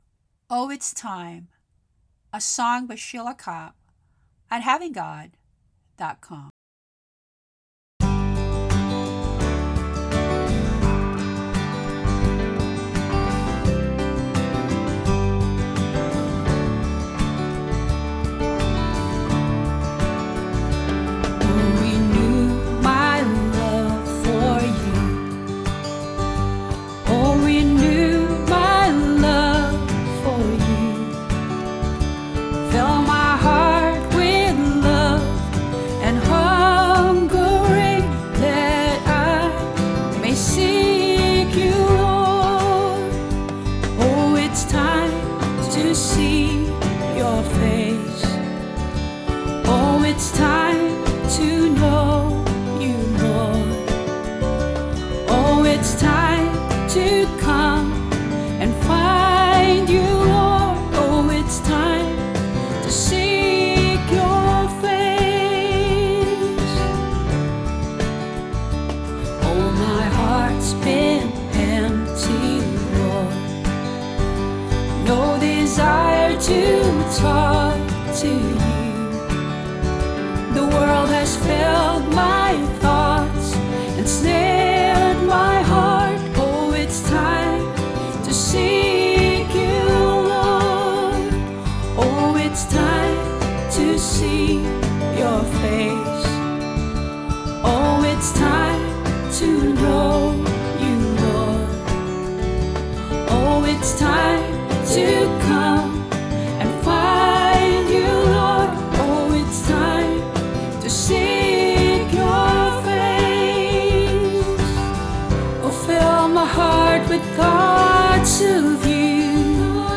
Arrangement produced through Band-In-A-Box